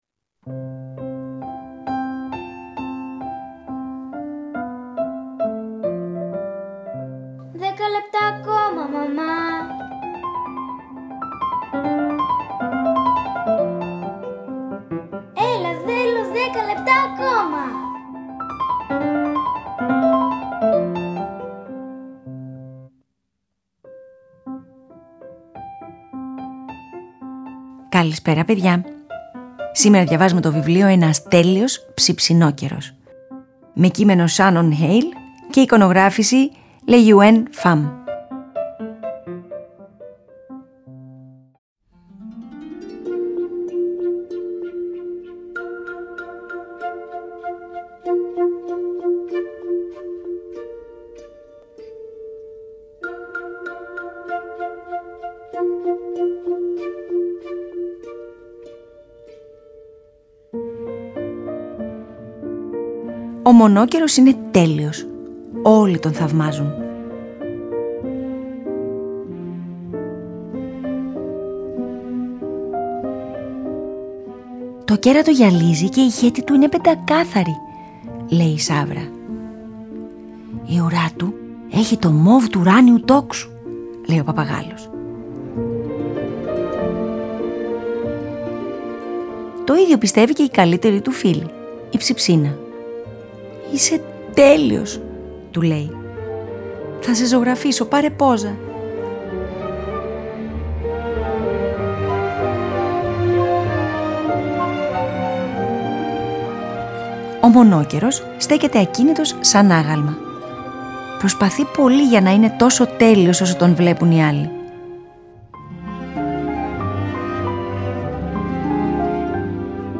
Αφήγηση-Μουσικές επιλογές: